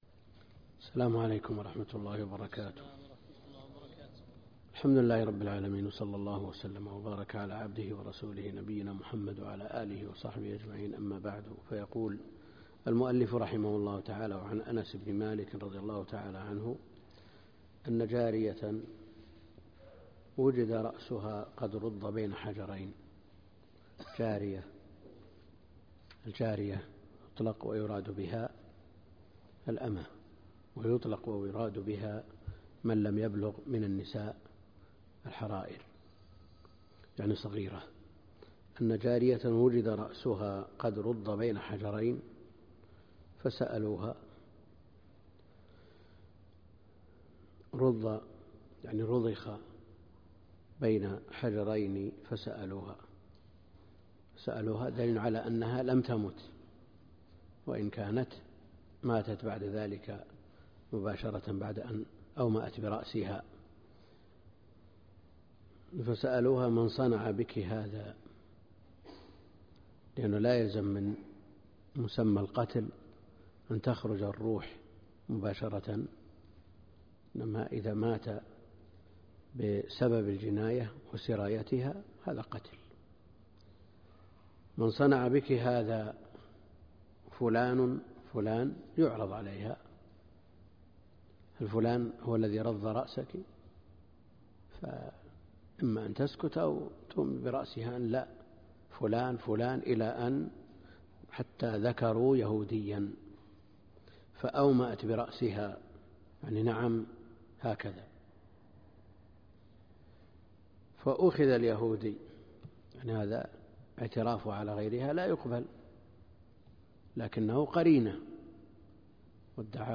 الدرس (2) كتاب الجنايا من بلوغ المرام - الدكتور عبد الكريم الخضير